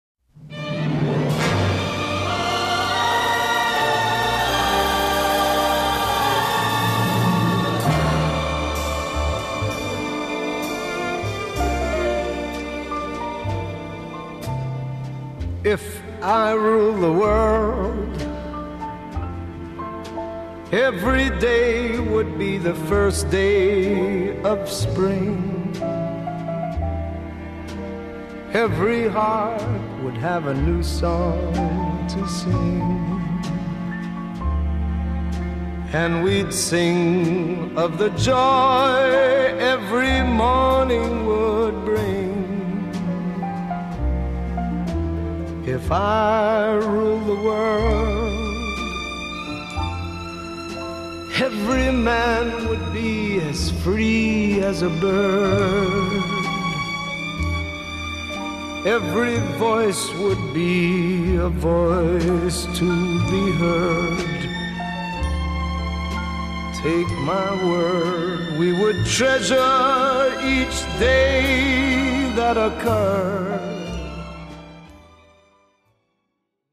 Жанр: R&B • Soul • Blues • Jazz